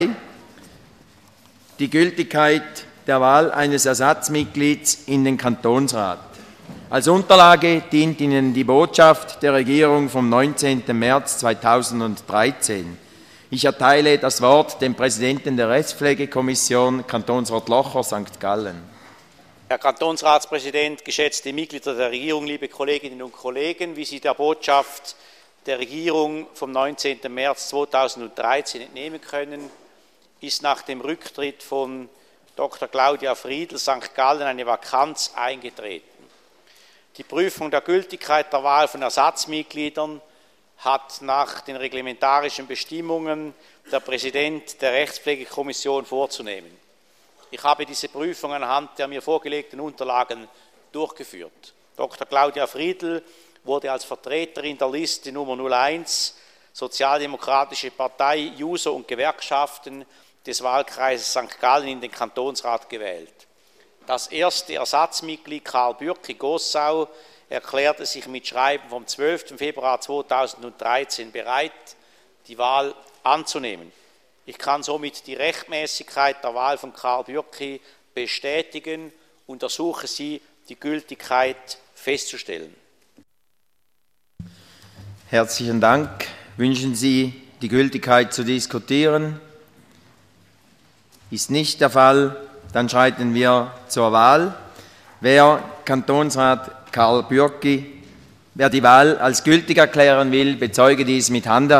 Präsident der Rechtspflegekommission: Der Gültigkeit der Wahl ist zuzustimmen.